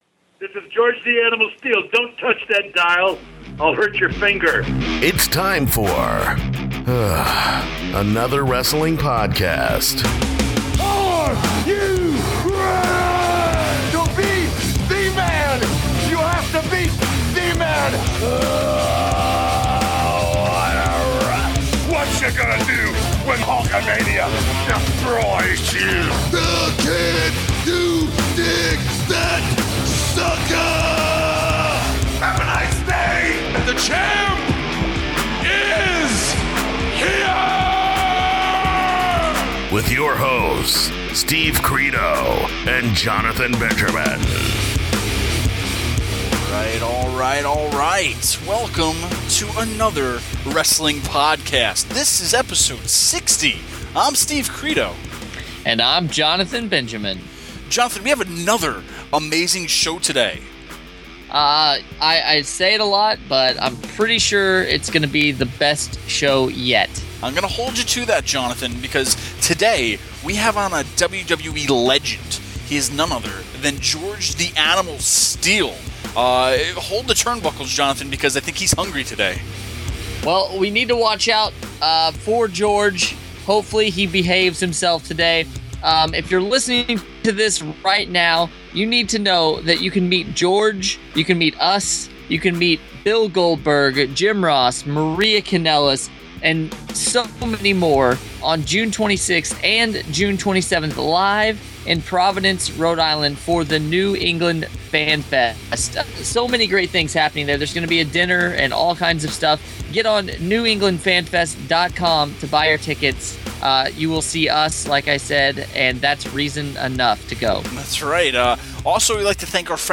In this episode the guys are talking about a time when everyone in the business had a gimmick based off an everyday job. Stopping by is WWE Hall of Famer George The Animal Steele to take a bite out of our show!